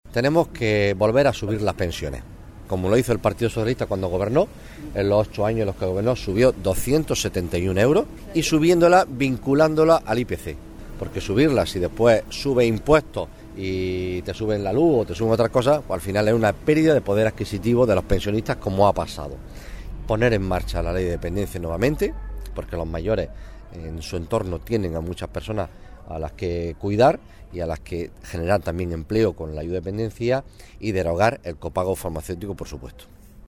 Visita que ha realizado al centro de la tercera edad de Las 500 Viviendas el cabeza de lista del PSOE de Almería al Senado, Juan Carlos Pérez Navas